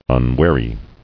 [un·war·y]